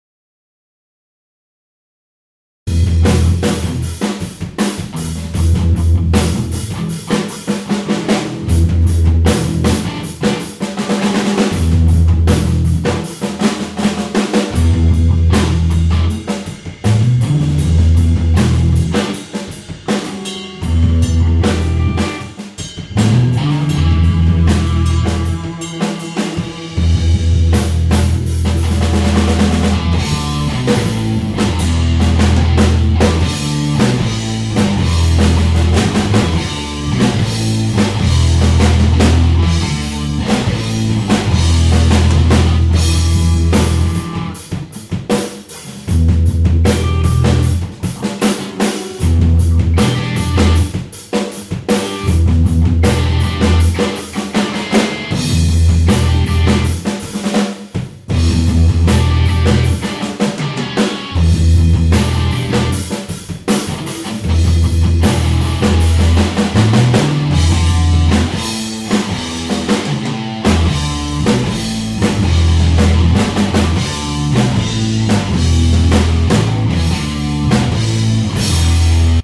Random Trio Jam 61015